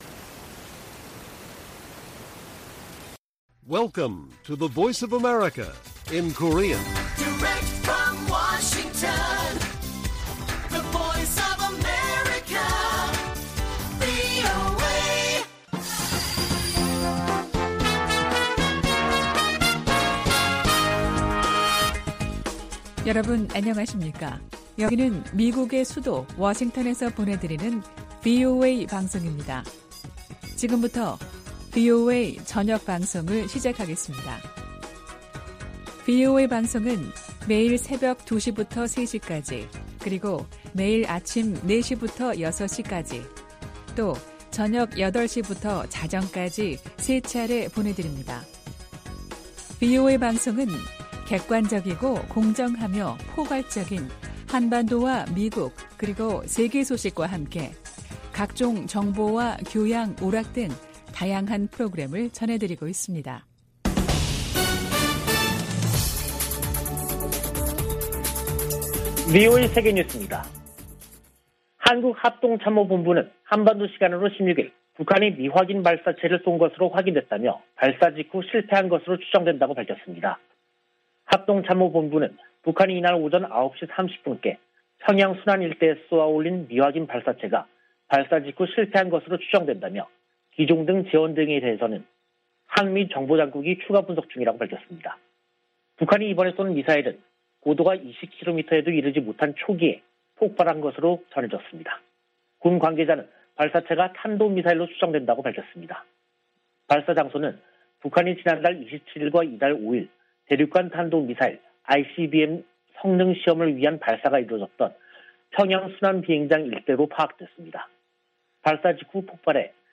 VOA 한국어 간판 뉴스 프로그램 '뉴스 투데이', 2022년 3월 16일 1부 방송입니다. 한국 합동참모본부는 북한이 16일 평양 순안 일대에서 미확인 발사체를 발사했으나 실패한 것으로 추정된다고 밝혔습니다. 미 국무부는 북한의 탄도미사일 시험발사를 규탄하고, 대화에 나서라고 촉구했습니다. 유럽연합(EU)은 북한의 최근 탄도미사일 발사와 관련해 추가 독자 제재 부과를 검토할 수 있다는 입장을 밝혔습니다.